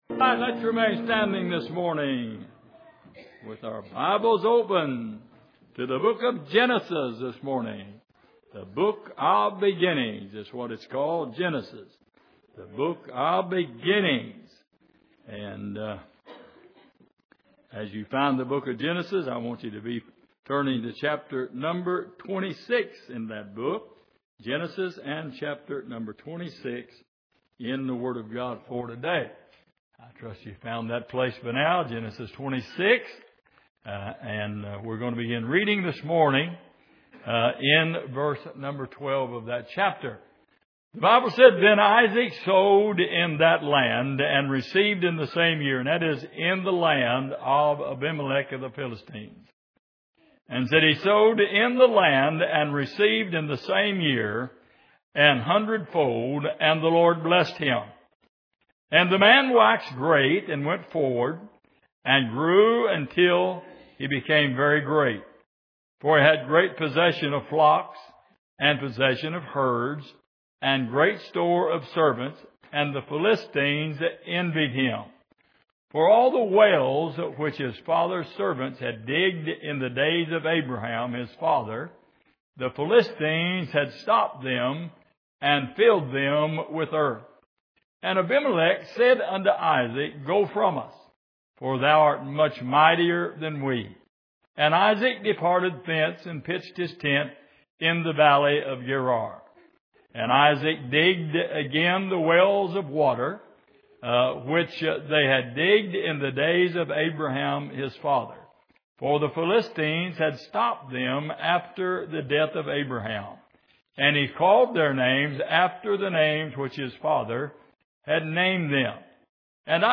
Genesis 26:12-25 Service: Sunday Morning What Stopped The Water?